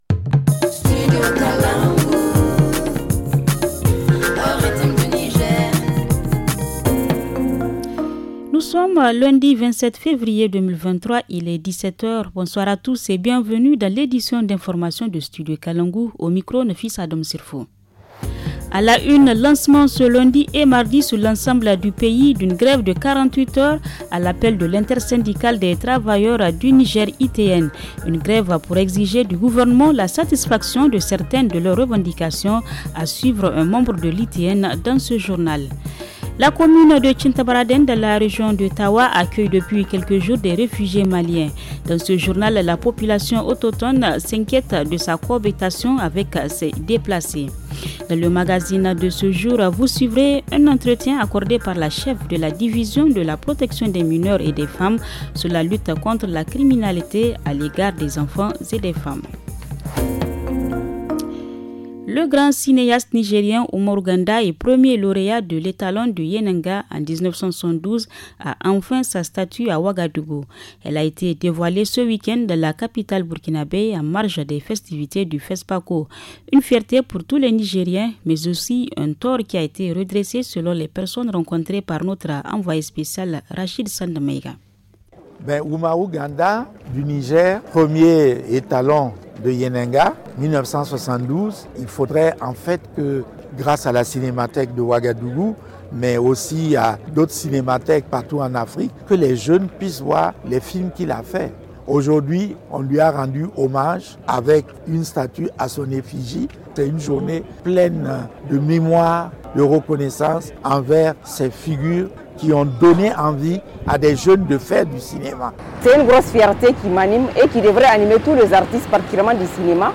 Le journal du 27 février 2023 - Studio Kalangou - Au rythme du Niger